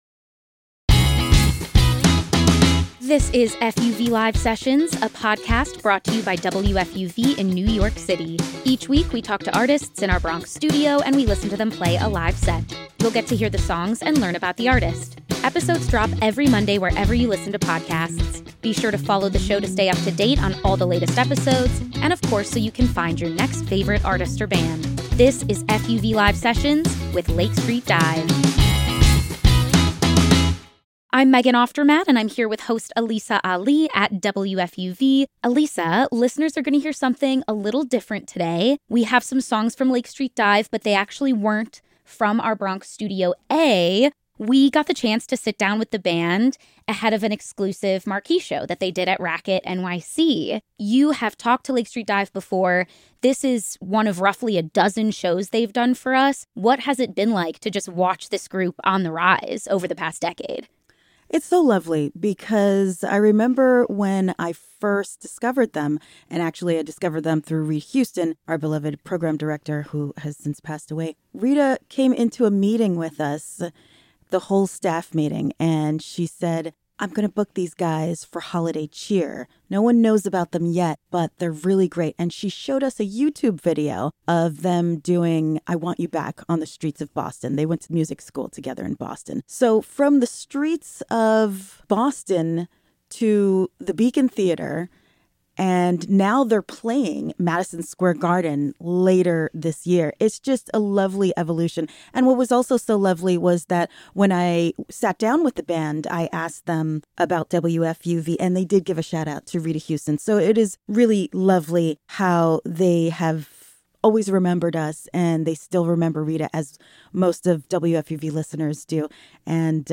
sits down with Lake Street Dive in Racket NYC’s greenroom